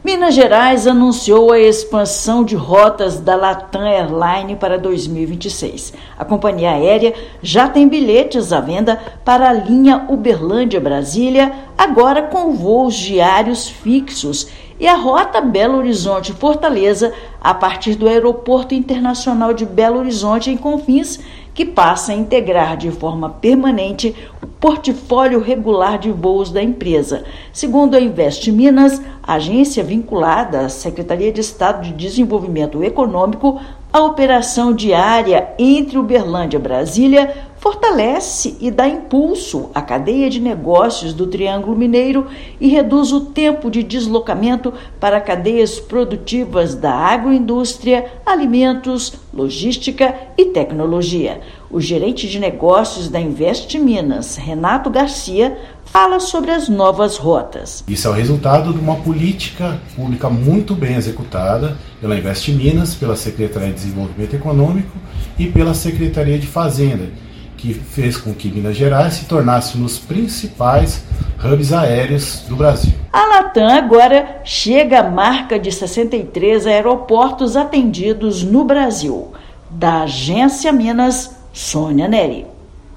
Parceria entre Governo de Minas e Latam Airlines garante avanço da malha aérea do estado com novas rotas e destinos permanentes no Brasil. Ouça matéria de rádio.